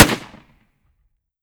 fps_project_1/30-30 Lever Action Rifle - Gunshot A 001.wav at 1b55bb993deda8bed02f9ad26d30e262c58601fa